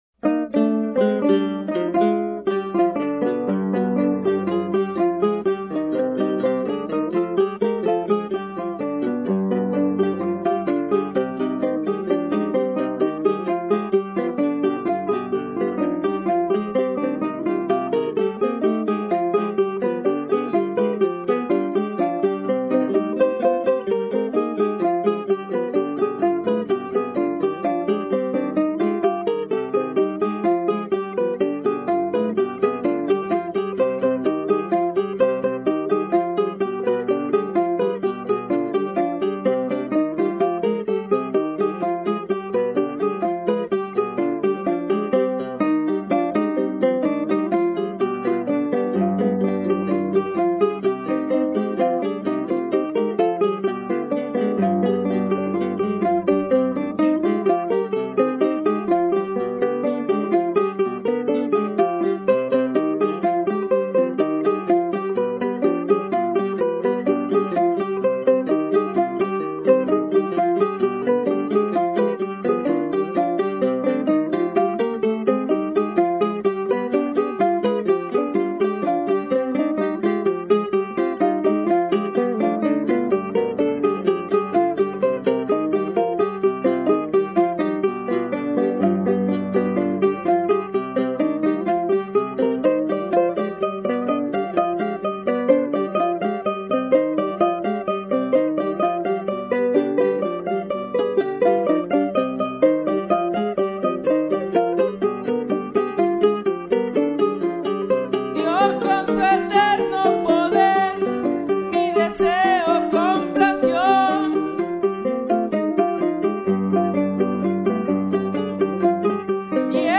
jarana tercera